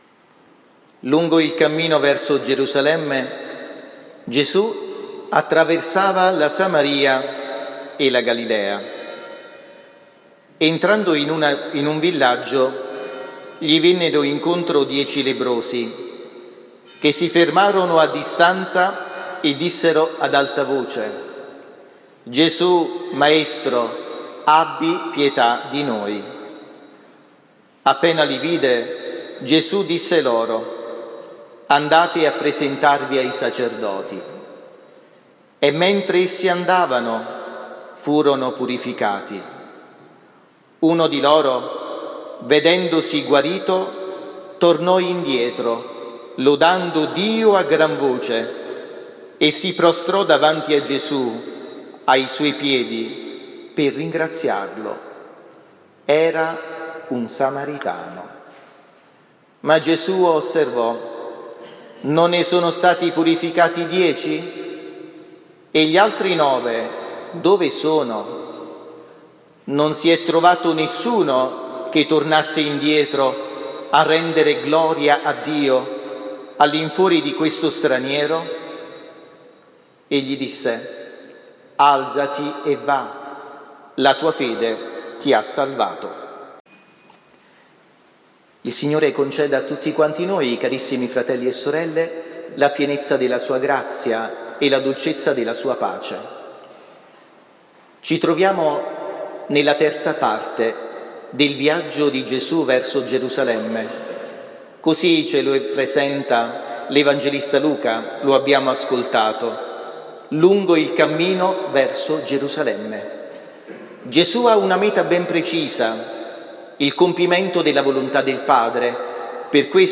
XXVIII Domenica del Tempo Ordinario (Anno C) – 09 ottobre 2022